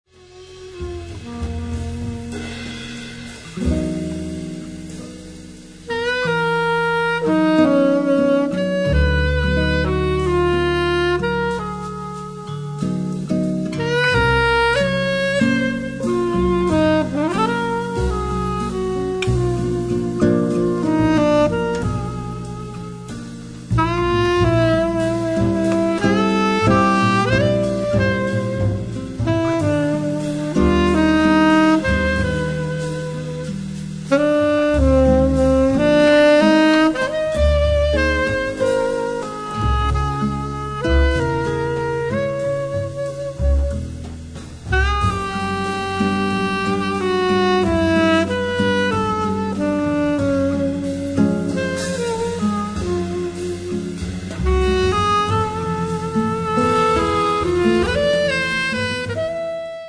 Sax tenore e soprano, clarinetto
Chitarra classica
Contrabbasso
Batteria